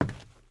WOOD.1.wav